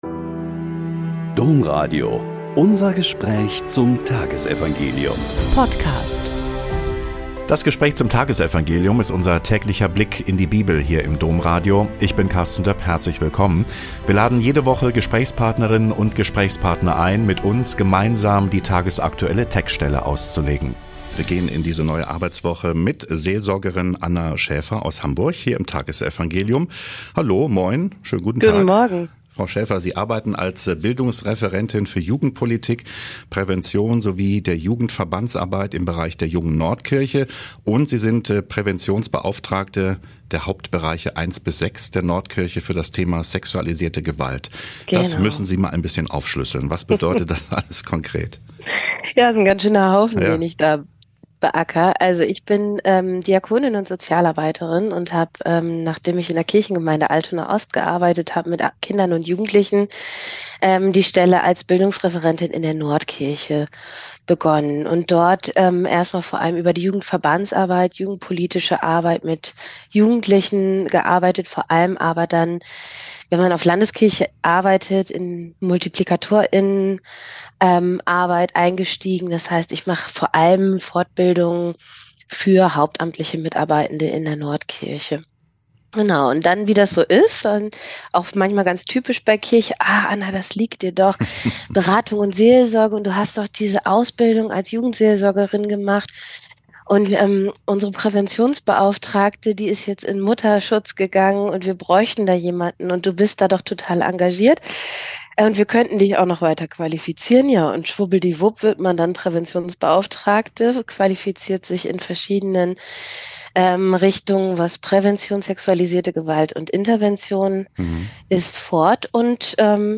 Lk 21,1-4 - Gespräch